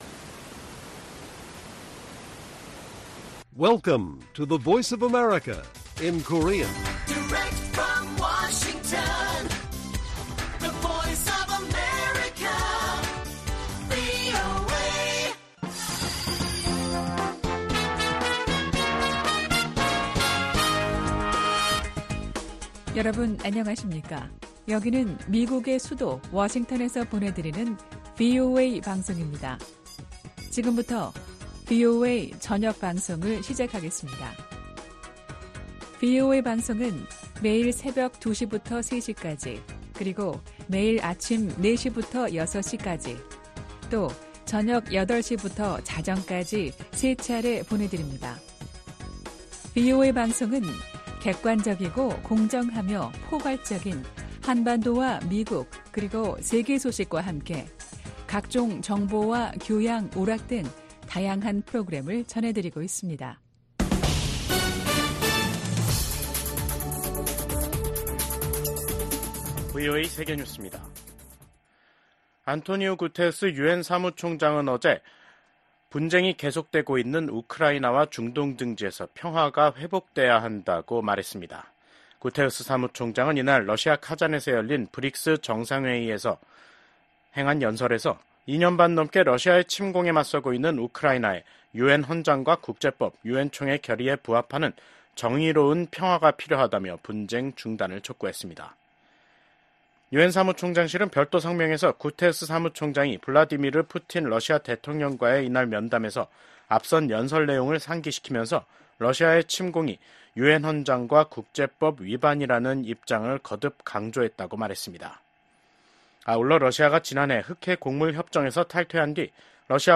VOA 한국어 간판 뉴스 프로그램 '뉴스 투데이', 2024년 10월 25일 1부 방송입니다. 미국 국방부는 러시아에 파병된 북한군이 우크라이나에서 전쟁에 참여할 경우 러시아와 함께 공동 교전국이 될 것이라고 경고했습니다. 미국 하원 정보위원장이 북한군의 러시아 파병과 관련해 강경한 대응을 촉구했습니다.